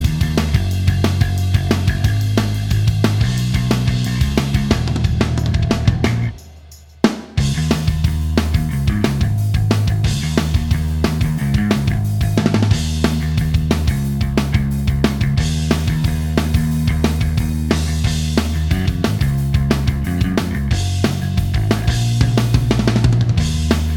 Minus All Guitars Rock 3:46 Buy £1.50